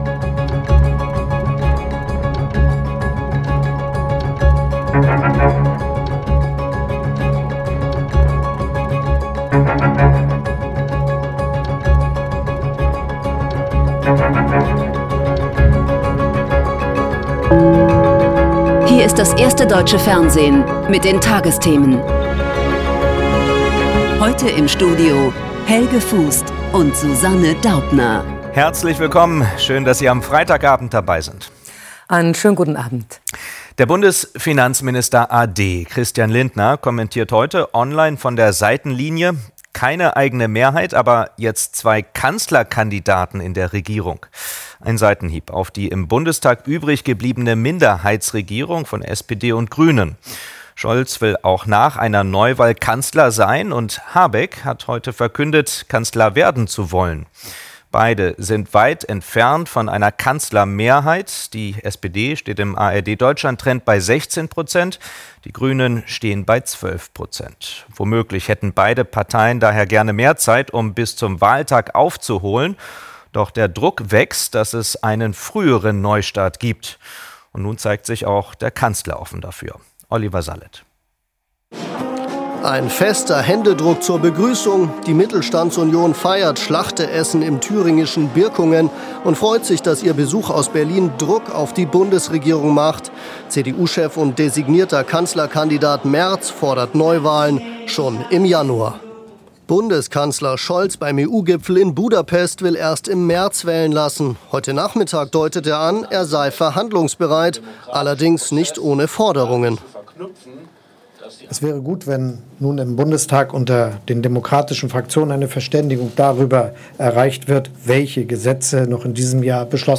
Die tagesthemen als Audio-Podcast.